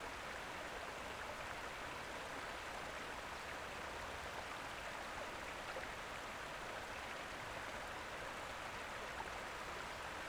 pond-ambience-2.wav